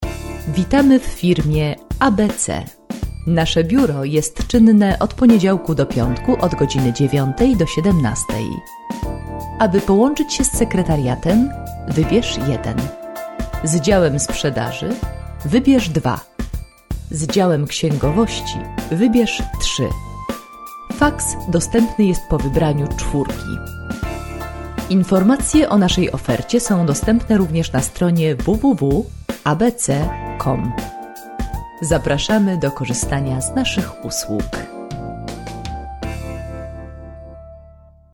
Female 30-50 lat
Nagranie lektorskie